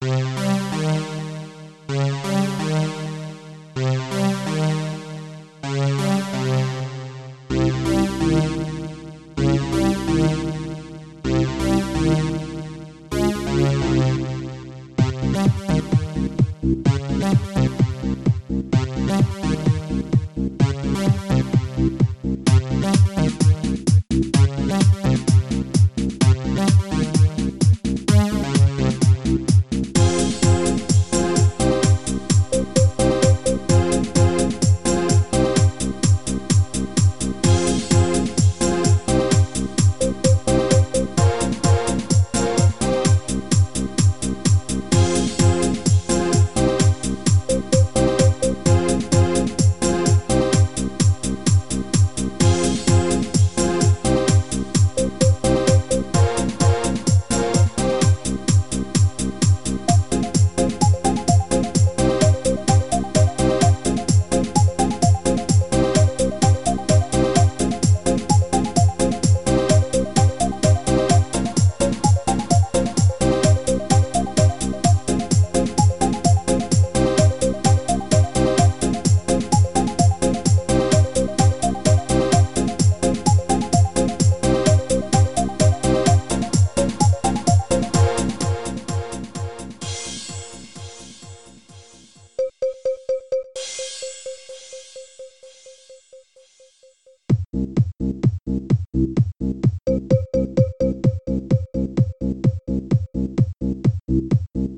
4-channel stereo dance MOD
• Music is loop-able, but also has an ending